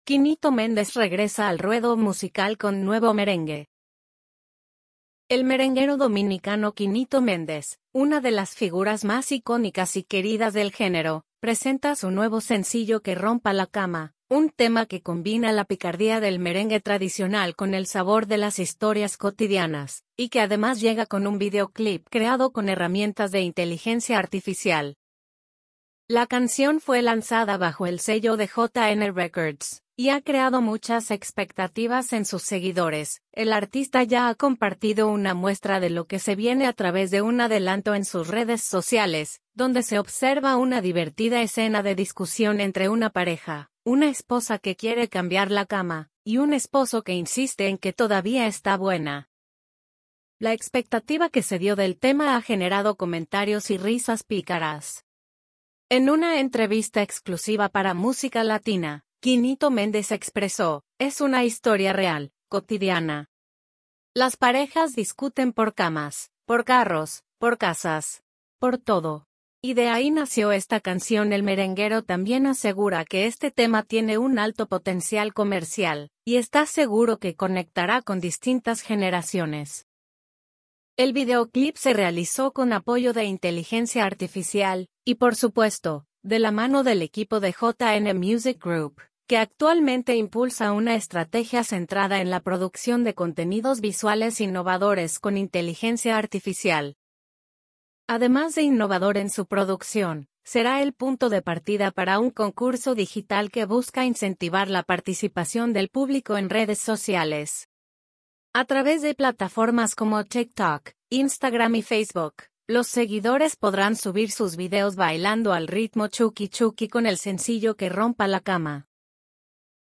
ritmo Chuqui Chuqui